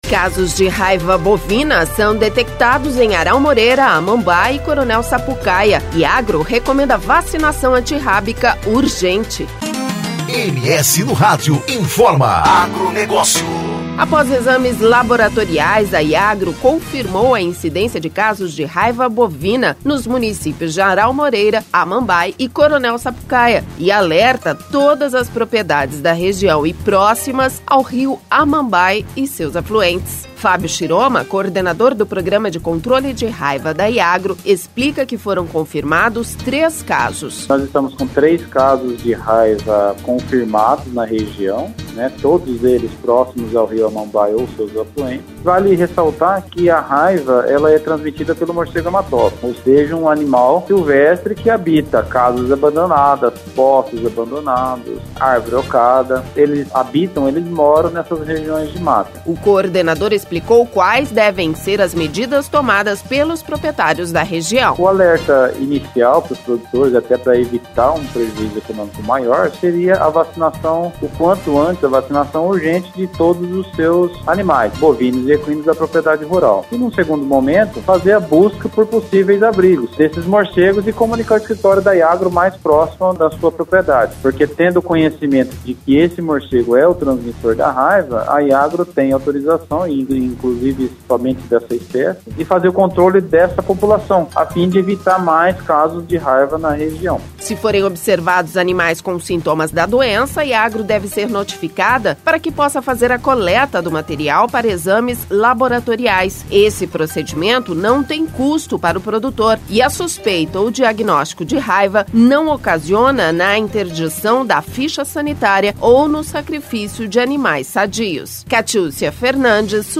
O técnico explicou quais devem ser as medidas tomadas pelos proprietários da região.